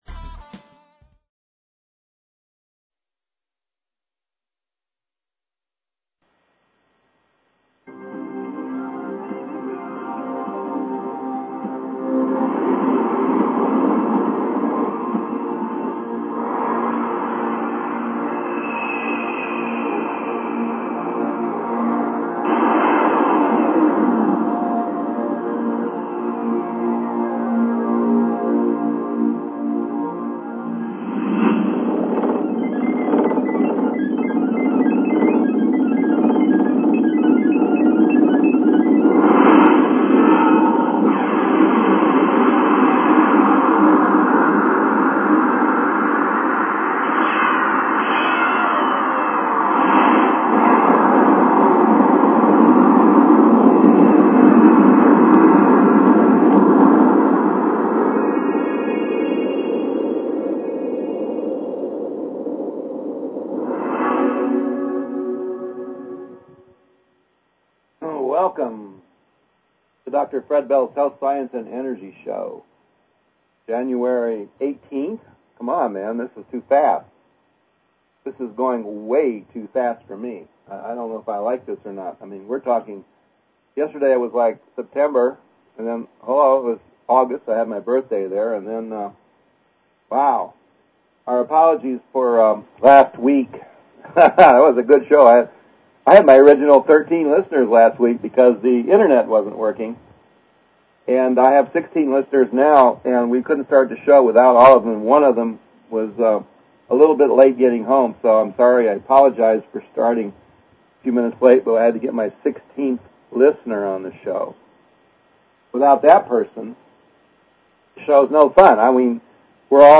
Call in show.
A generally wide variety of topics for BBS listeners. These shows are for the listeners to share their views and experiences.